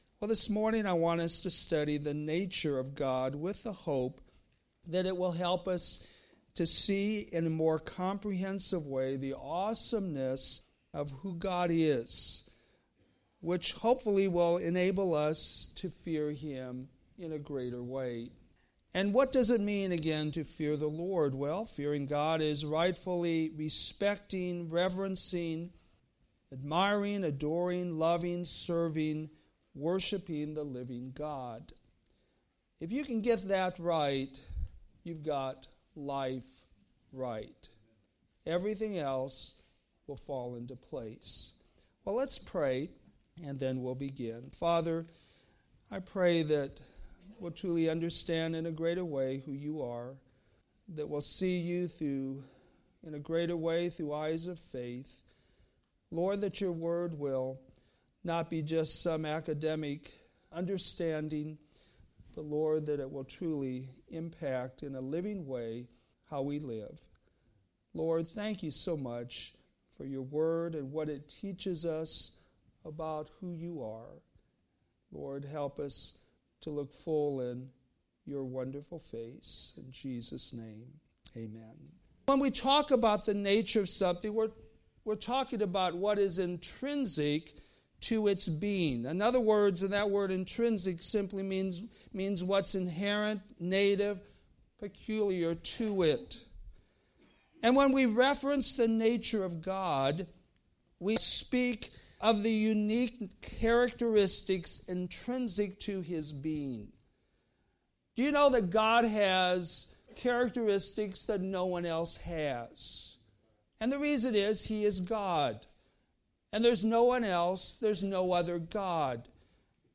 All Sermons - Westside Baptist Church